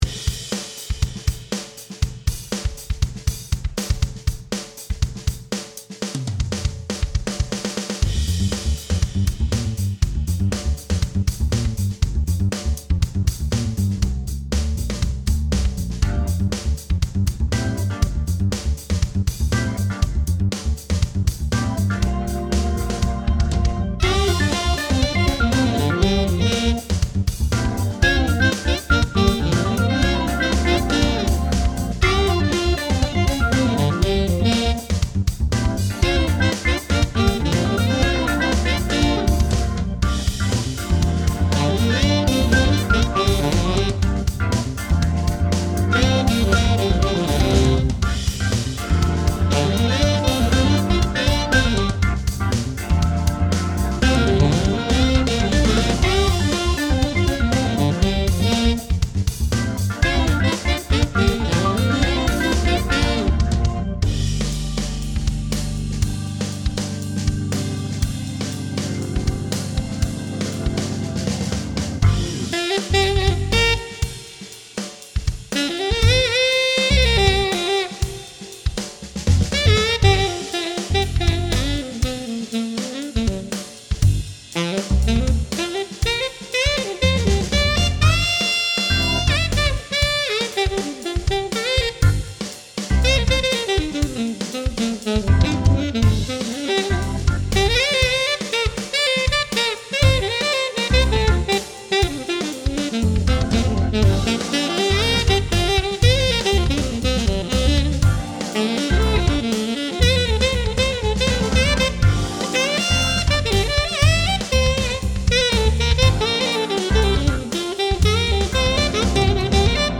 I have also been making some digital pieces on my own while anticipating being able to meet with other musicians again.